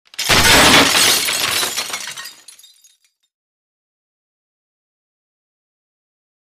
Impacts, Glass | Sneak On The Lot
Metal And Glass Impact With Broken Glass Tinkling